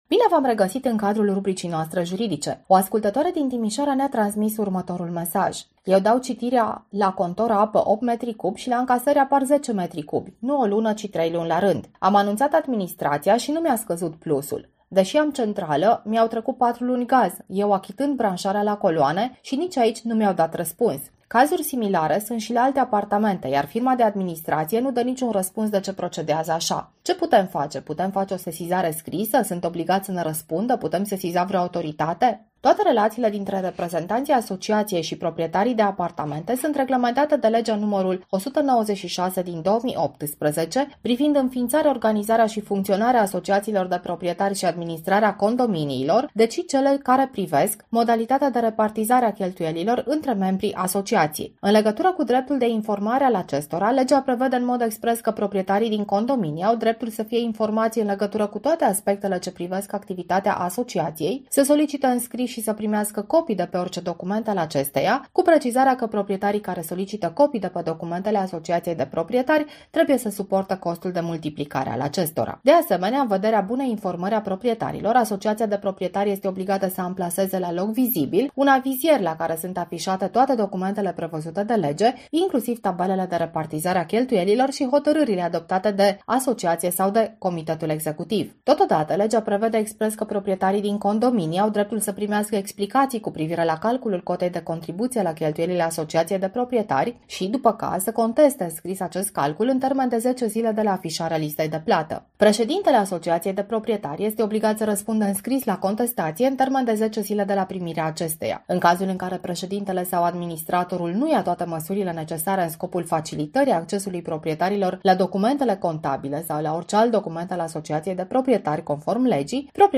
Rubrica juridică este difuzată  pe frecvențele noastre în fiecare zi de luni.